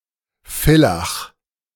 ˈfɪlax, slovinsky Beljak, italsky Villaco, česky Bělák) je druhým největším městem spolkové země Korutany v jižním Rakousku a sedmým největším městem celého Rakouska.